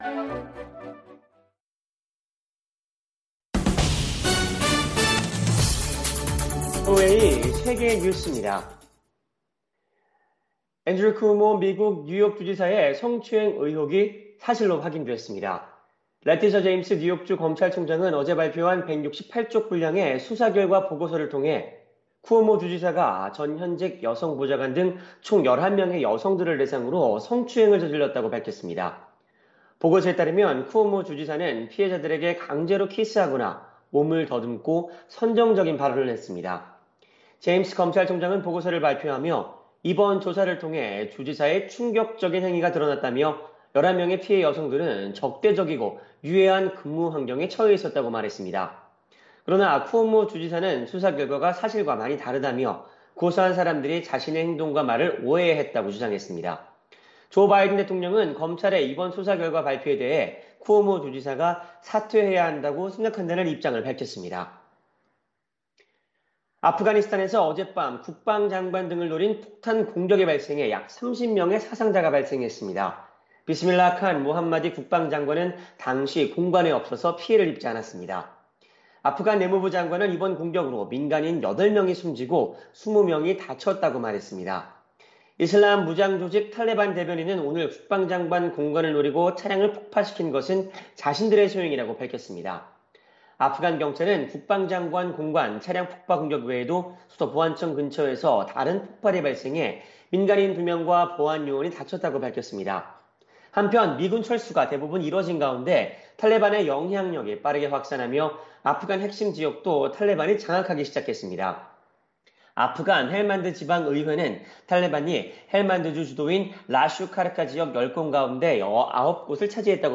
생방송 여기는 워싱턴입니다 저녁
세계 뉴스와 함께 미국의 모든 것을 소개하는 '생방송 여기는 워싱턴입니다', 저녁 방송입니다.